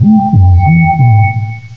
cry_not_musharna.aif